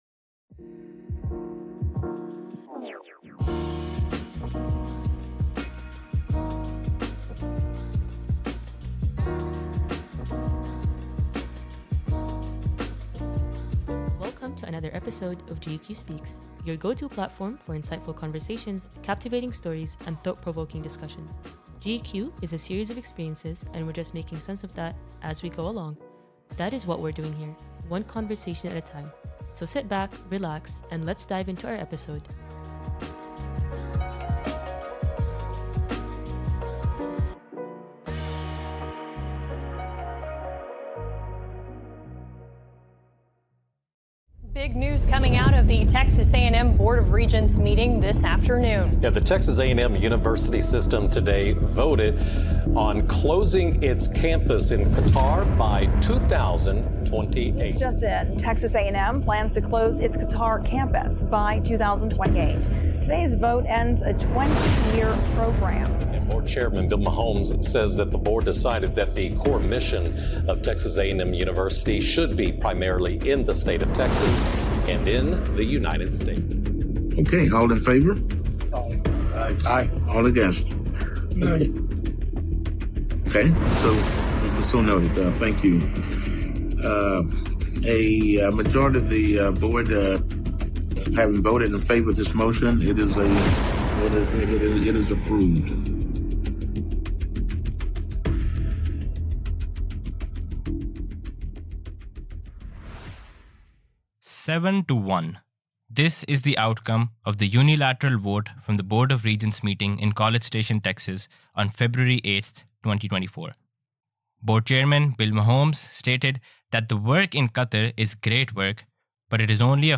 In this episode, podcast hosts discuss the recent closure of the Texas A&M campus in Qatar with some students there.